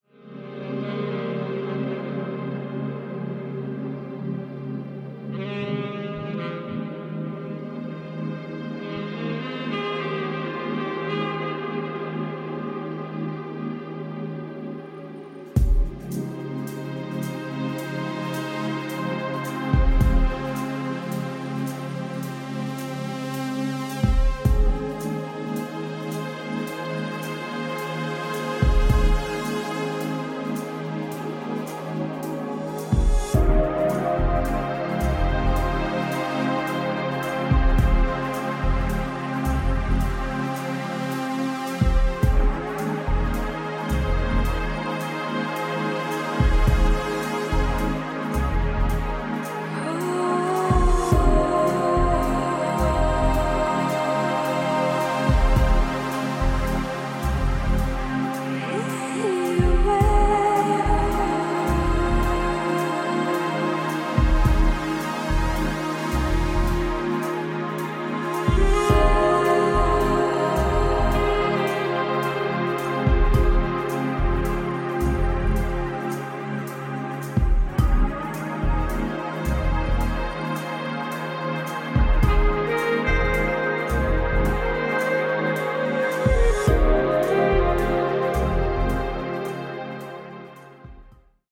Dreamy Diary of an Enchanting Duo
Disco House Boogie Soul